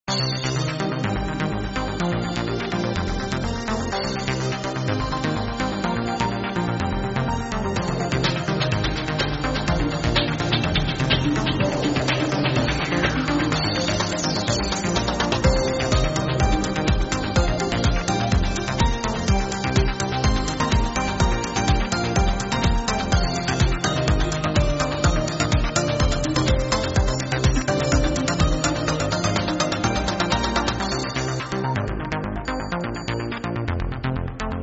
Estilo: Pop
Pista musical para jingles estilo “pop”
Calidad de la muestra (48kbps) ⬅Dale click al Play